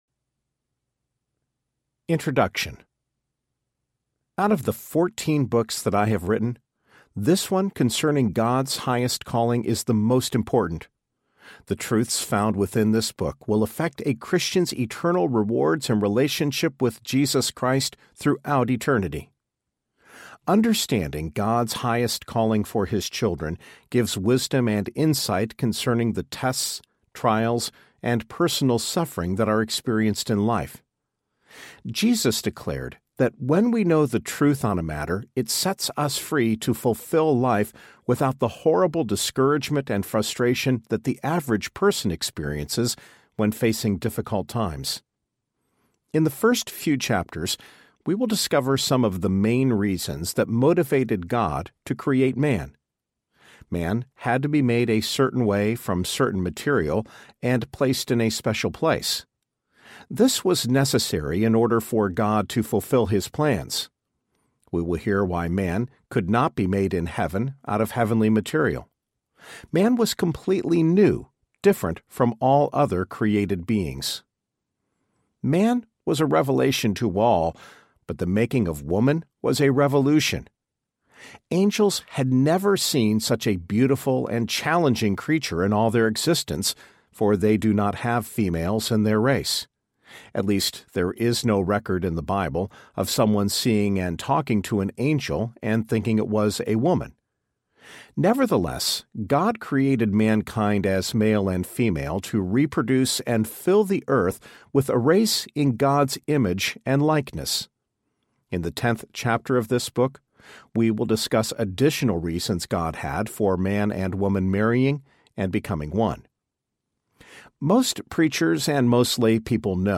Your Highest Calling Audiobook
Narrator
6.3 Hrs. – Unabridged